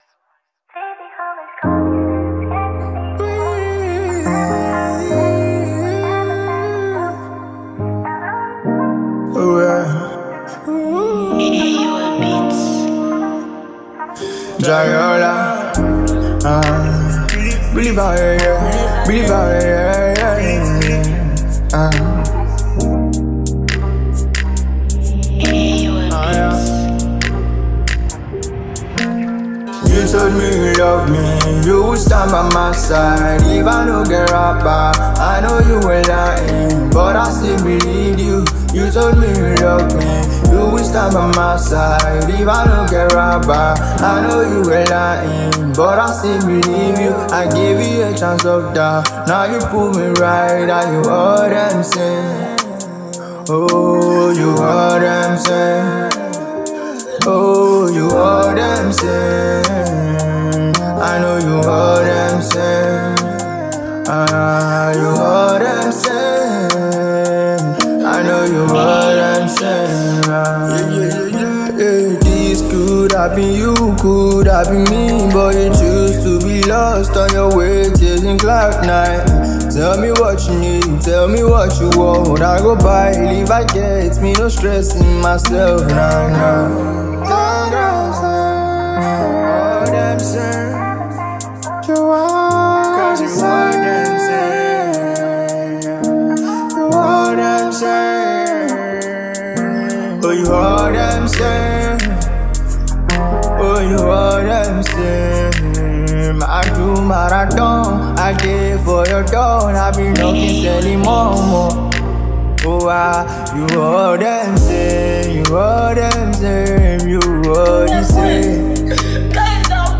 Hiphop and Rap single